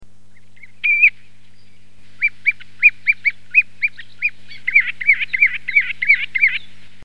Grand Gravelot
Charadrius  hiaticula
gravelot.mp3